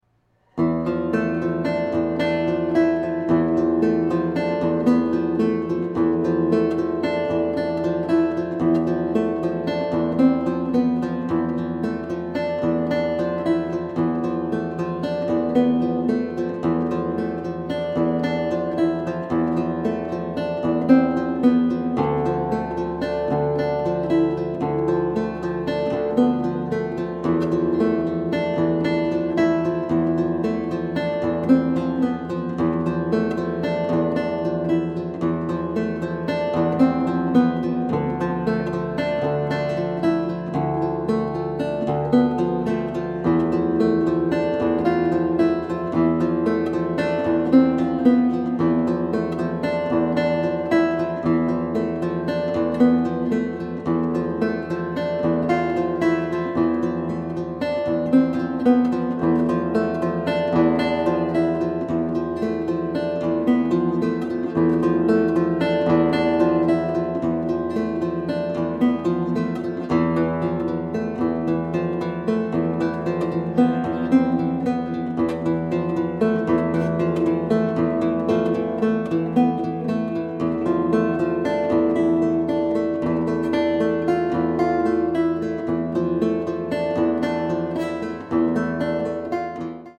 composer, lute & oud player from Japan
Contemporary